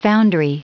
Prononciation du mot foundry en anglais (fichier audio)
Prononciation du mot : foundry